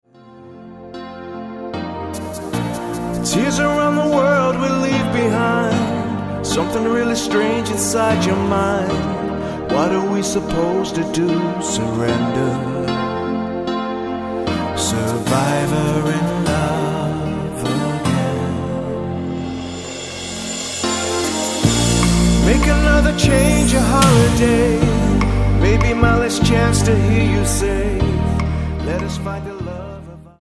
Tonart:Bb-C Multifile (kein Sofortdownload.
Die besten Playbacks Instrumentals und Karaoke Versionen .